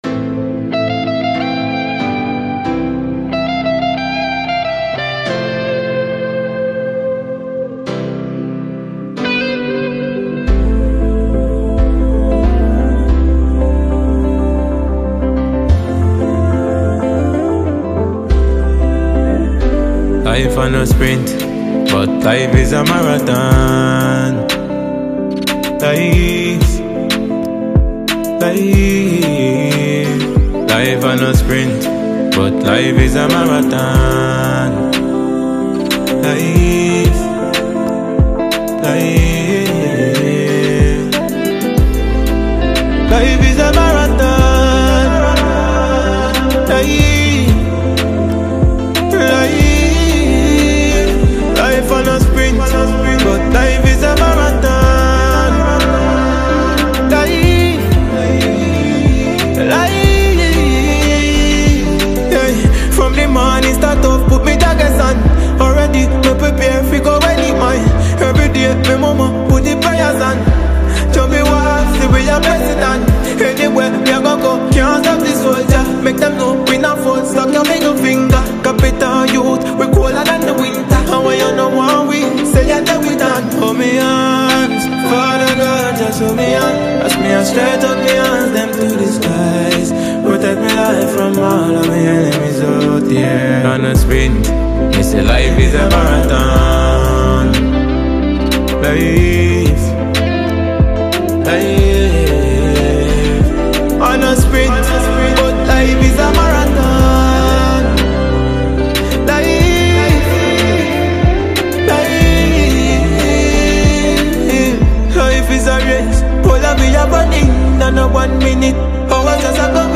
Ghanaian dancehall musician